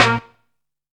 HOWL HIT.wav